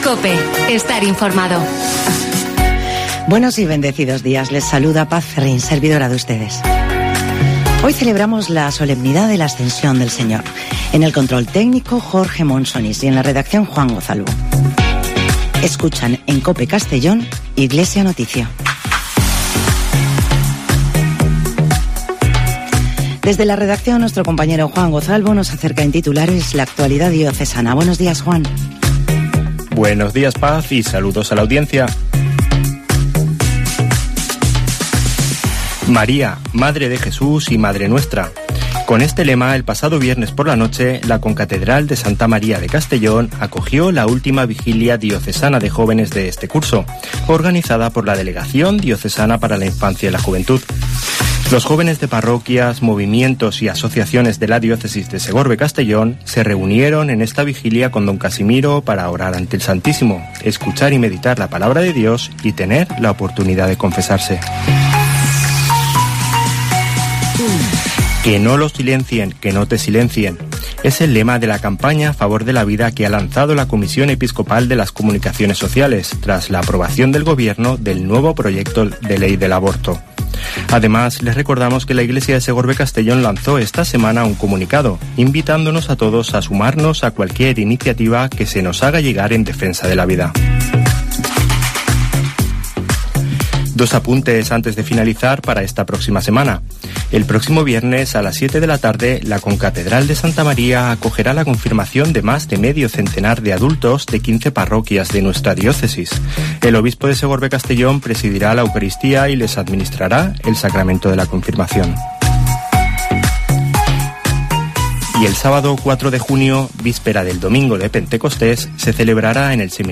Espacio informativo de la Diócesis de Segorbe-Castellón